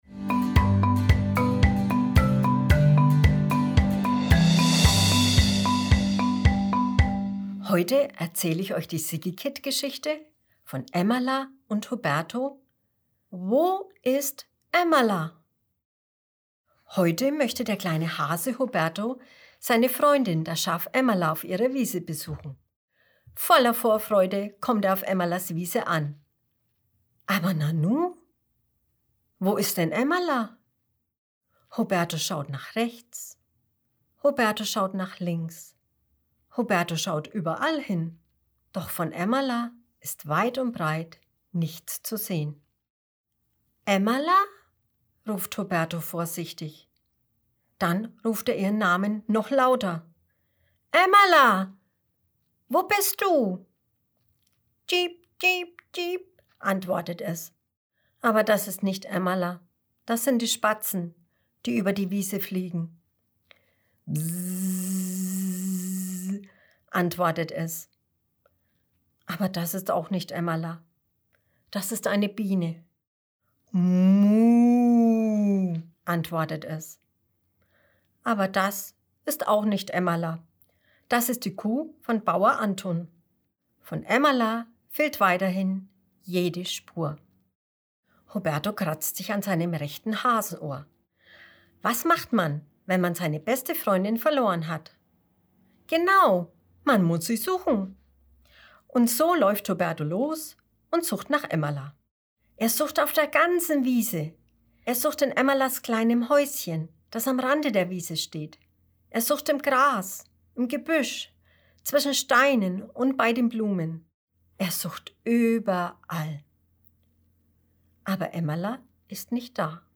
April 2020 Kinderblog Vorlesegeschichten, Emmala & Huberto Huberto möchte heute seine Freundin Emmala auf der Wiese besuchen.